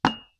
Impact
Ceramic on Wood 1.wav